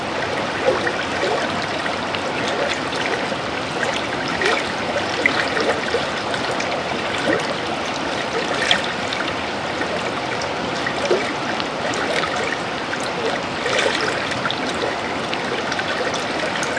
Es regnet fast die ganze Zeit über, ist aber mehr ein nieseln und nur selten etwas mehr. Die Klamm ist schön anzusehen und die "Große Ohe" stürzt sich die glattgelutschten Brocken herab.